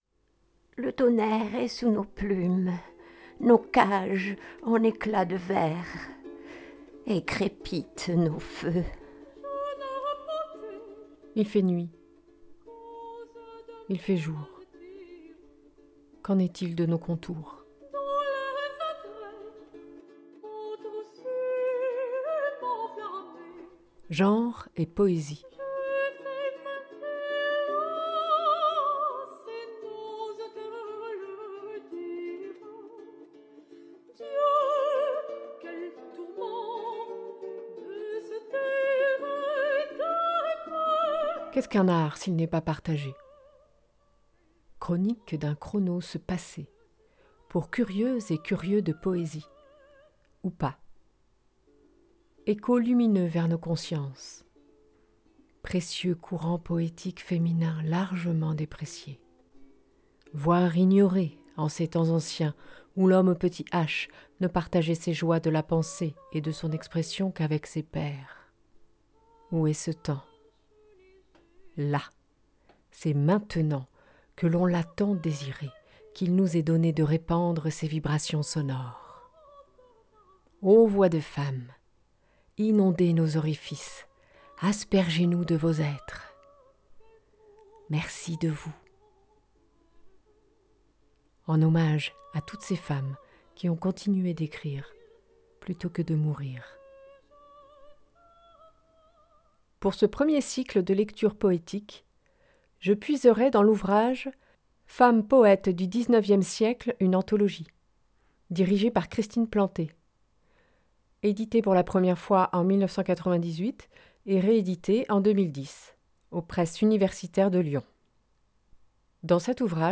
Une chronique produite par RadioLà